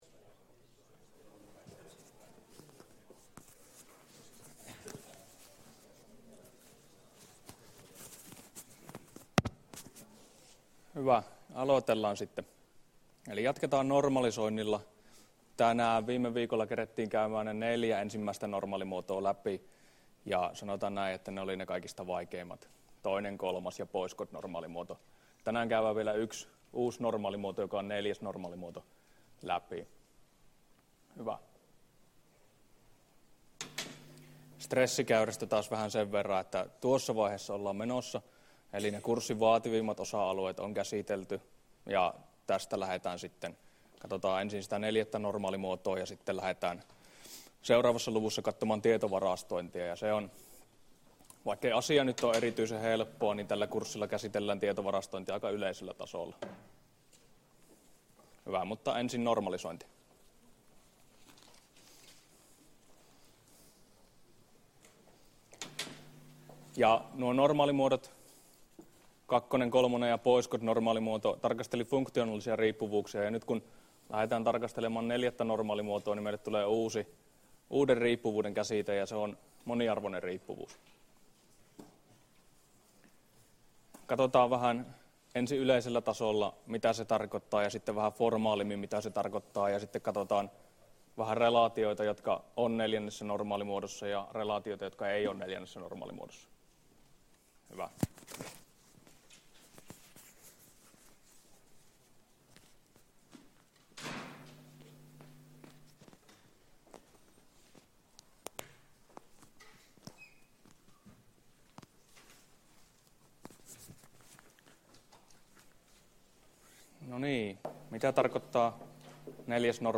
Luento 13 — Moniviestin